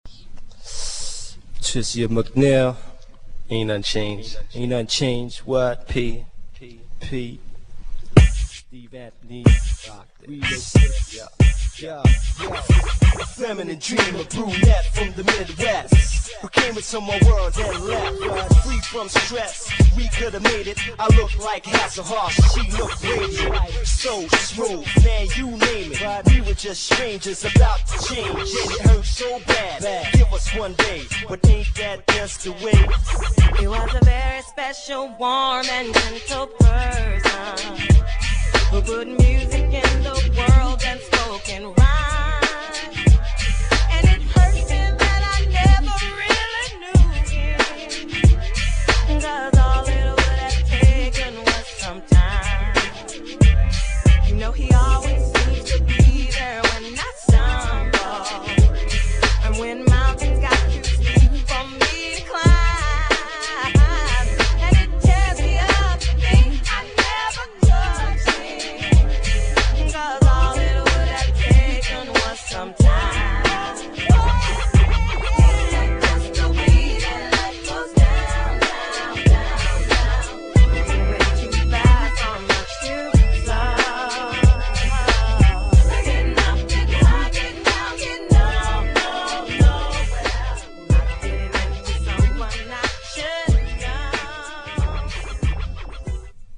GENRE R&B
BPM 96〜100BPM
90sポップ
featにRAP # HIPHOPテイスト
タイトなビートがイイ # 女性VOCAL_R&B